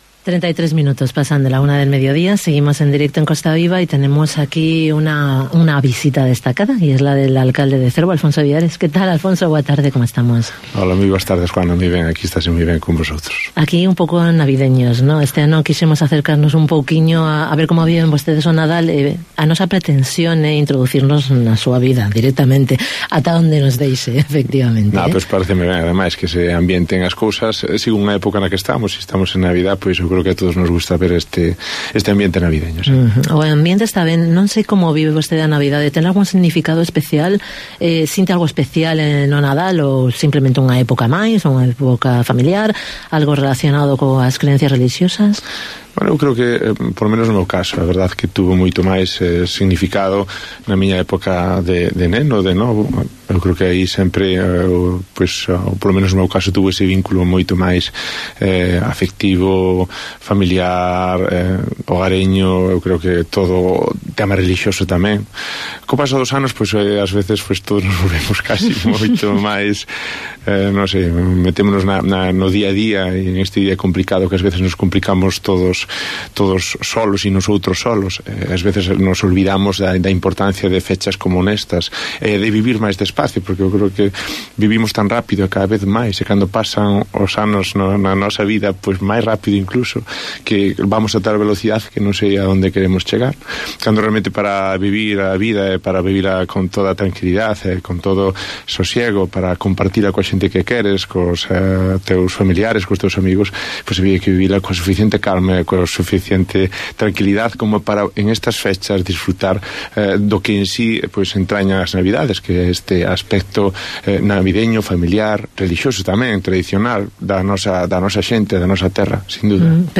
ENTREVISTA con Alfonso Villares, alcalde de Cervo